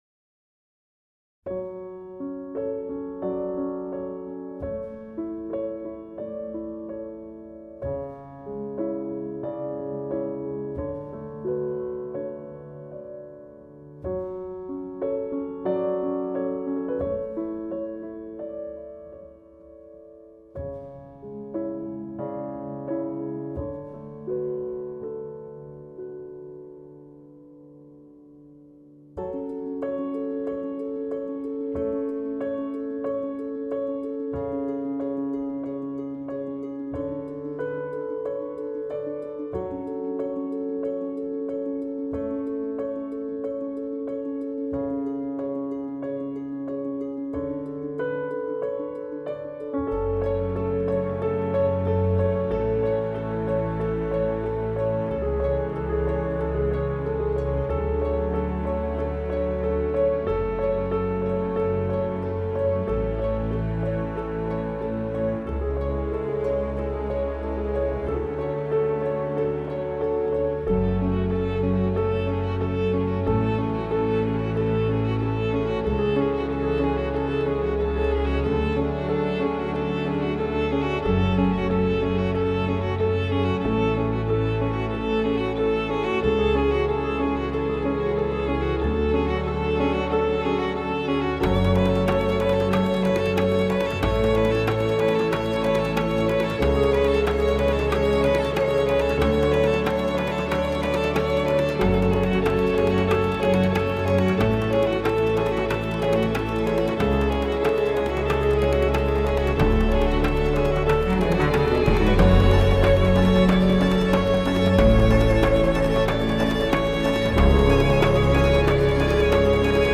موسیقی بیکلام